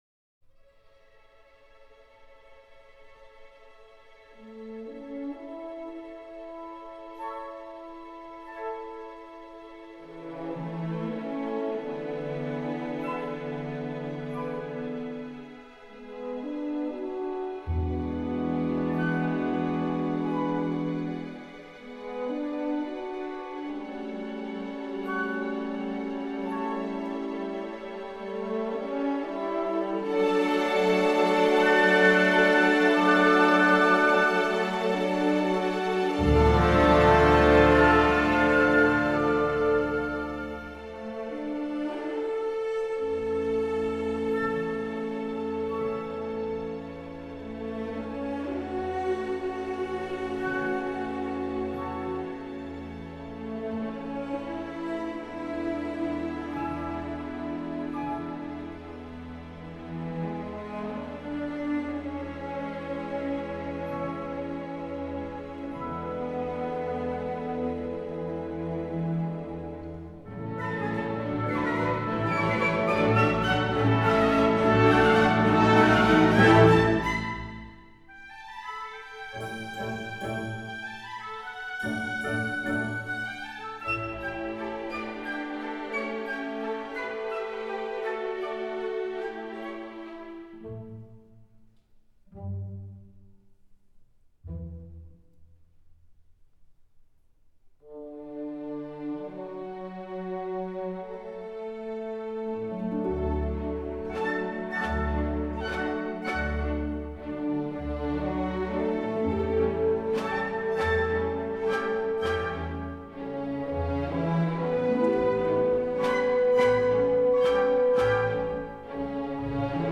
Вальс Иоганна Штрауса младшего "На прекрасном голубом Дунае"
Темп постепенно ускоряется, все завершается торжественным восходом солнца.
Мелодия основной части напоминает течение великой реки.
Вначале плавные и спокойные, они все убыстряются, словно спеша за рекой.